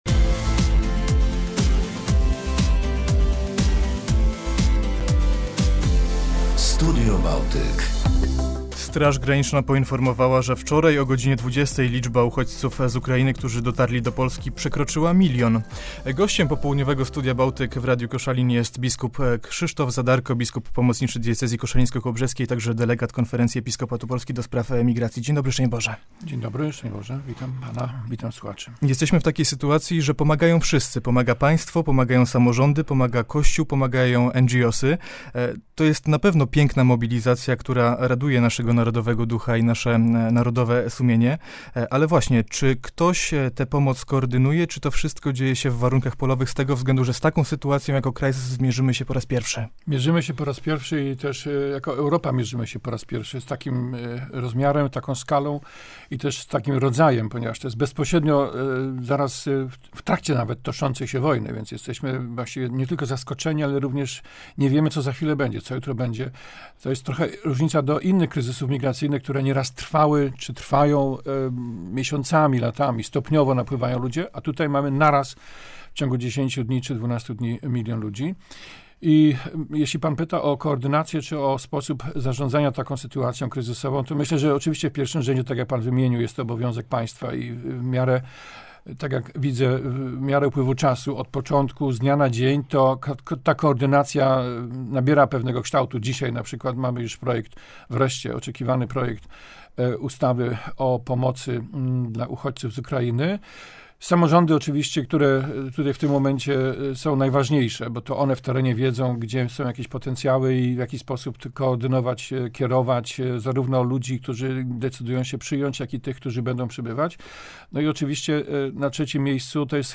Straż Graniczna poinformowała, że wczoraj o godzinie 20.00 liczba uchodźców z Ukrainy, którzy dotarli do Polski przekroczyła milion. W radiowej rozmowie pojawiają się różne konteksty związane z rosyjską inwazją na Ukrainę, a także pomocą humanitarną dla uchodźców prowadzoną w Polsce.
Nagranie dzięki uprzejmości Polskiego Radia Koszalin.